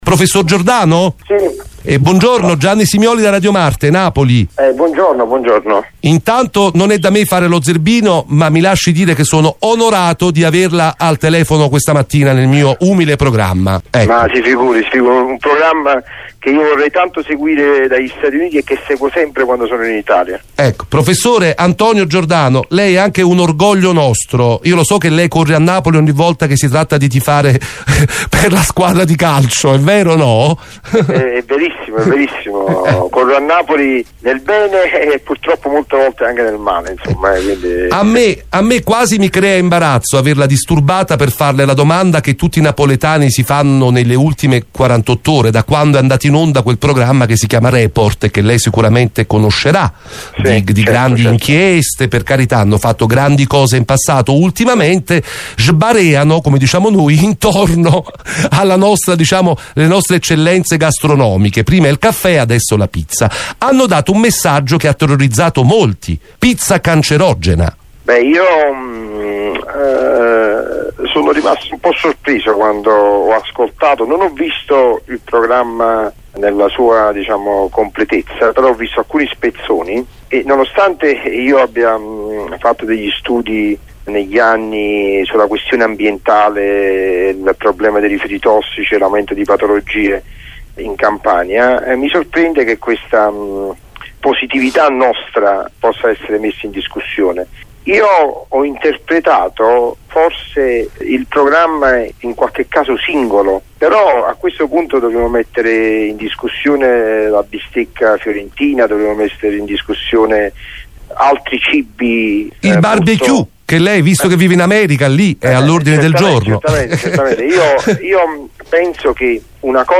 Ascolta l’intervista: INTERVISTA DOTT.